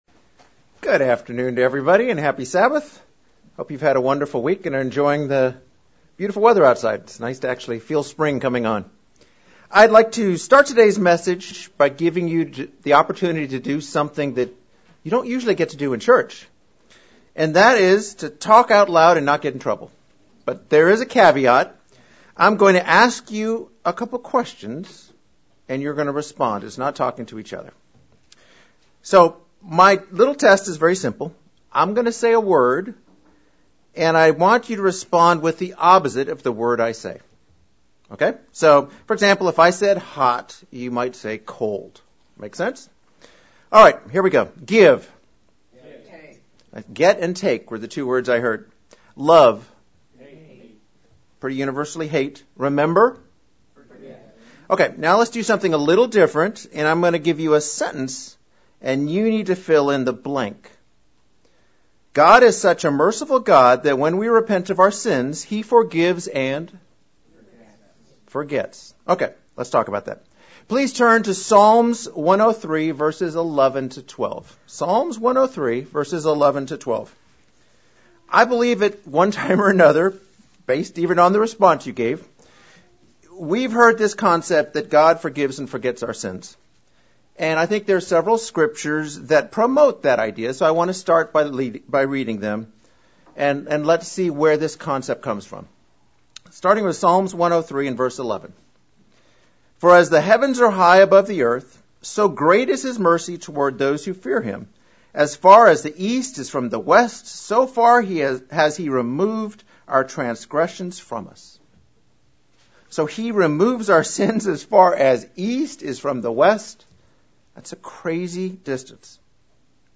Split sermon. Discussion of how does God forget our sins and how we should use these lessons as applications in forgiving others.